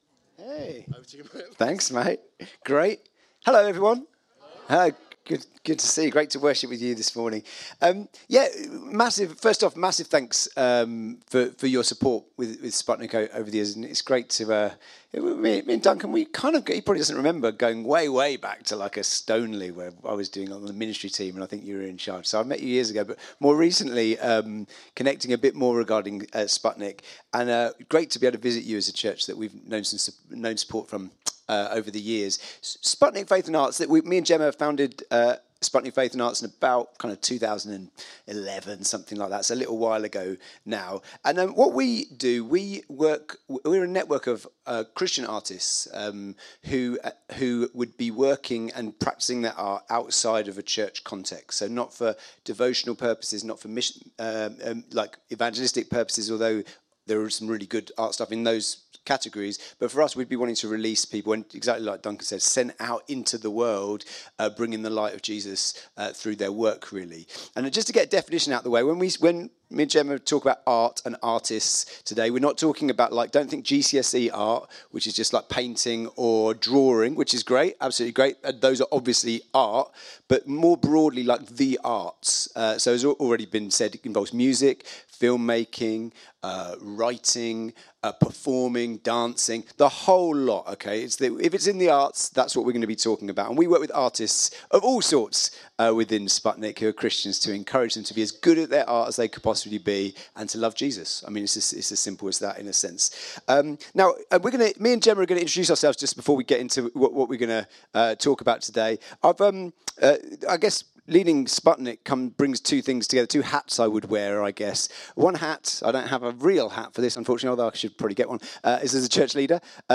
Download Sent Into The Creative Arts | Sermons at Trinity Church